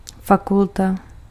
Ääntäminen
Synonyymit pouvoir possibilité aptitude UFR virtualité fac Ääntäminen France: IPA: [fa.kyl.te] Haettu sana löytyi näillä lähdekielillä: ranska Käännös Ääninäyte Substantiivit 1. fakulta {f} Suku: f .